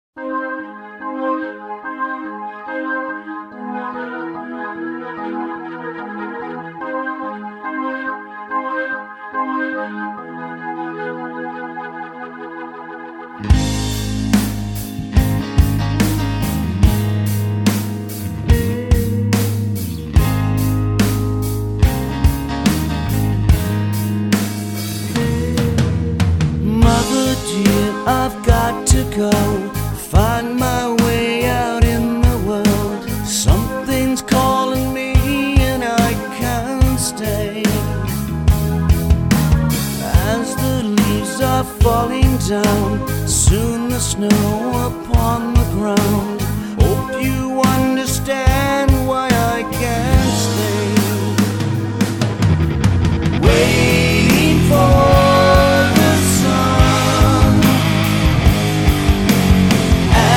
Metal
Бескомпромиссный hard&heavy metal